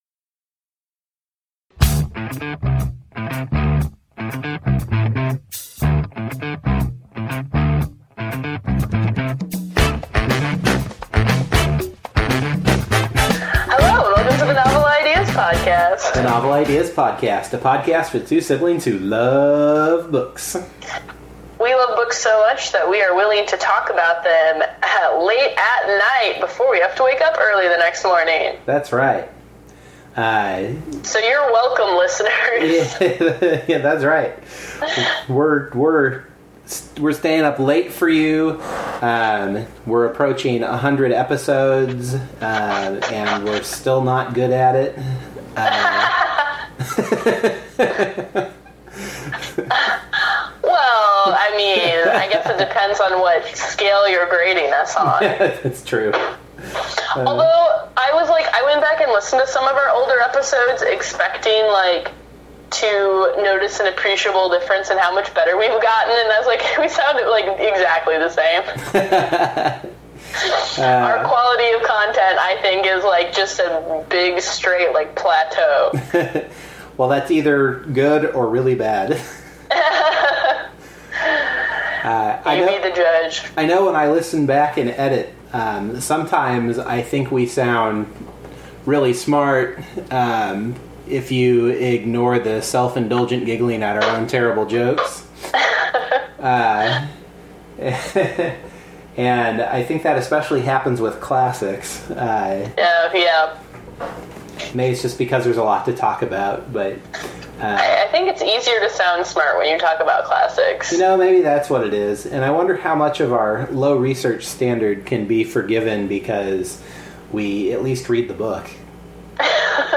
This month we discussed Shadowshaper by Daniel Jose Older, a recent YA urban fantasy novel. In this episode you hear a punchy and tired Novel Ideas crew talk about YA character archetypes, the nature of trust, race and racism, and authentic teenage dialogue.
There is about ten minutes of weirdness in the audio around the forty minute mark, caused by I don’t know what. Our apologies to those listening in stereo sound, one channel cuts in and out for a while.